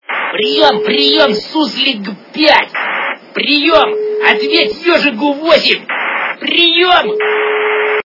» Звуки » другие » Голос - Прием, прием суслег 5, ответь ежигу 8
При прослушивании Голос - Прием, прием суслег 5, ответь ежигу 8 качество понижено и присутствуют гудки.